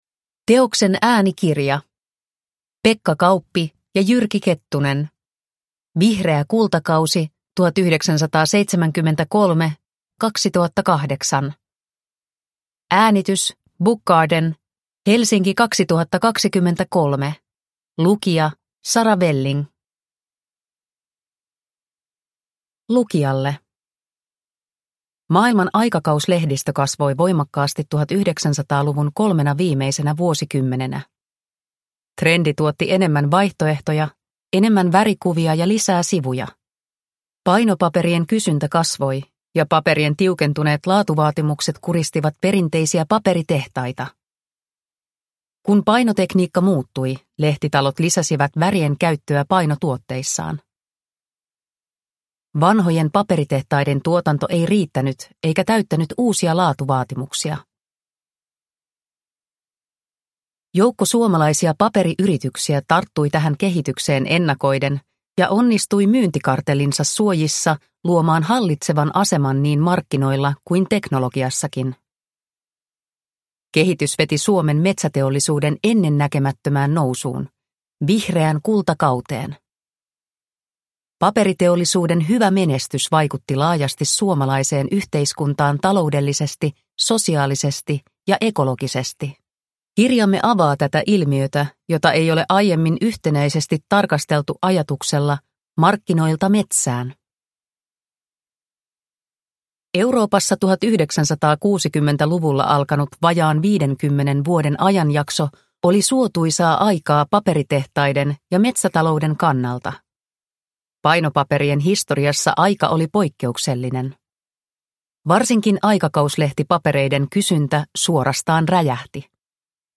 Vihreä kultakausi 1973-2008 – Ljudbok – Laddas ner